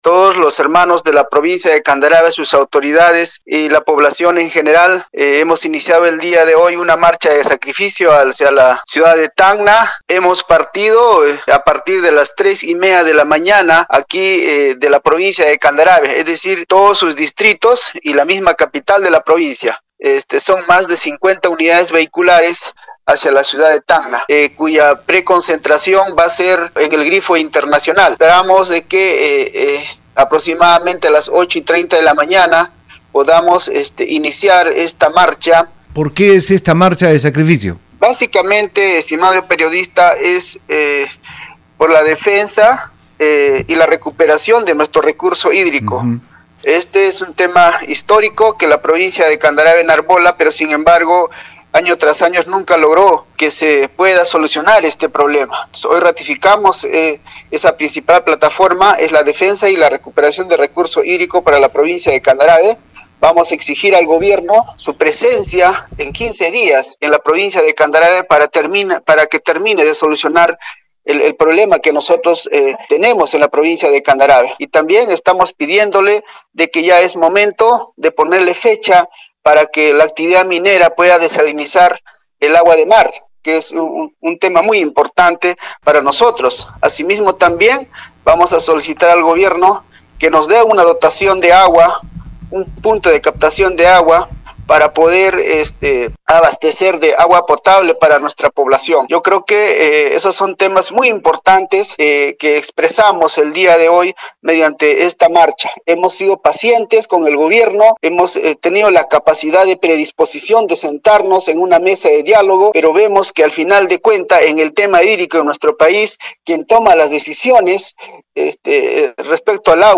Marcha de sacrificio por el agua realiza este lunes 20 la provincia de Candarave cuya población se moviliza a Tacna desde las 3:00 a. m., indicó el alcalde provincial Rafael Vega precisando que «son más de 50 unidades vehiculares que se dirigen hacía la ciudad de Tacna cuya concentración será en el grifo Internacional».
rafel-vega-alcalde-de-candarave.mp3